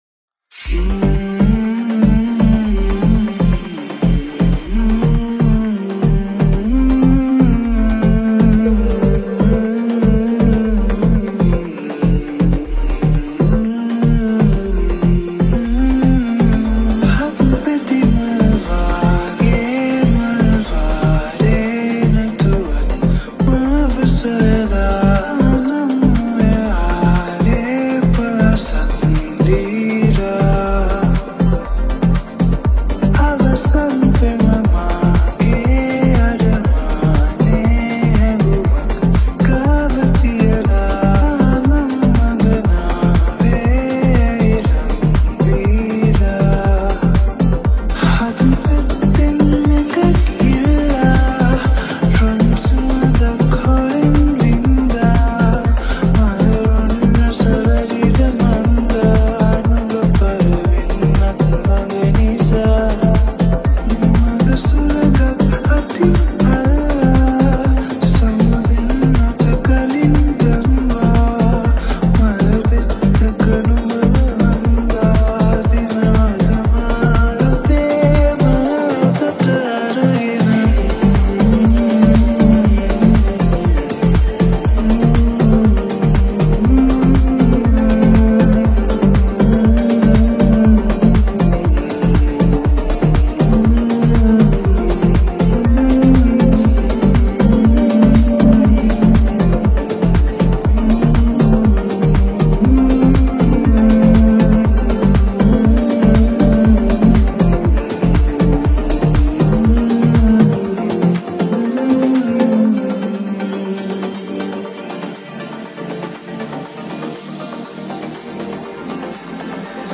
Sinhala Progressive House